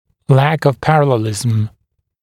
[læk əv ‘pærəlelɪzəm][лэк ов ‘пэрэлэлизэм]недостаточная параллельность